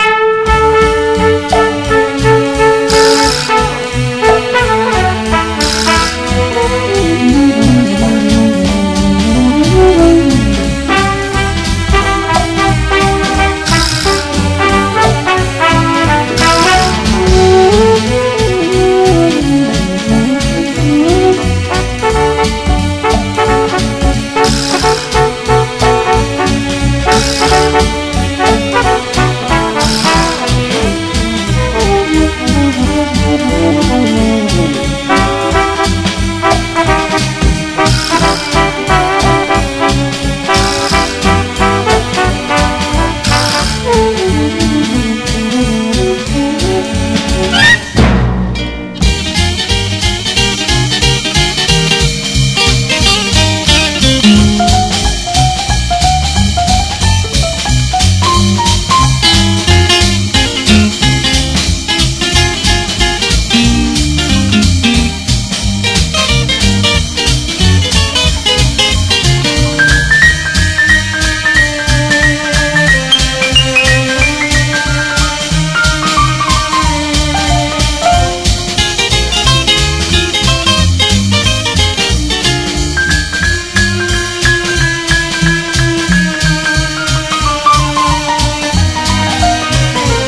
SOUL / SOUL / FREE SOUL / SOFT ROCK / S.S.W.